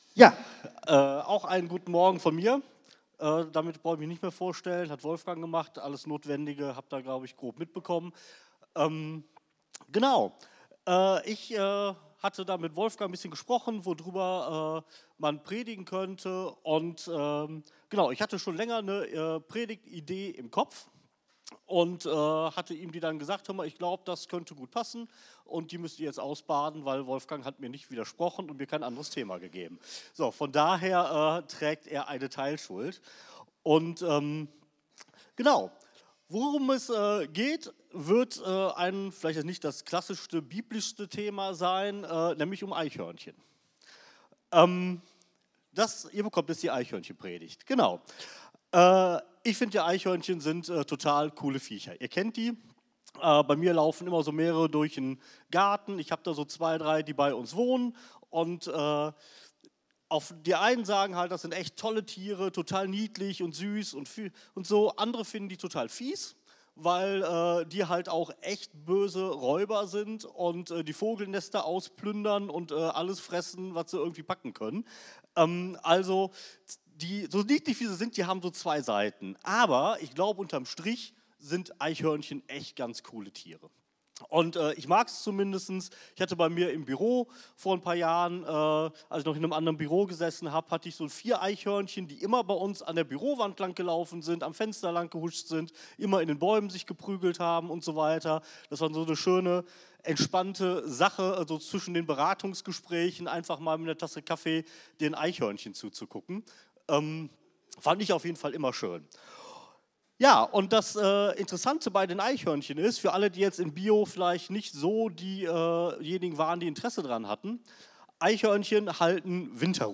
Eichhörnchen Predigt